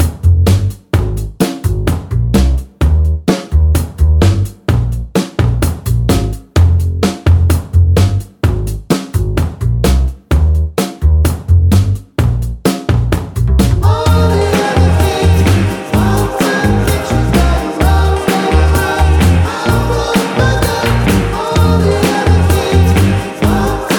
no Backing Vocals Indie / Alternative 3:55 Buy £1.50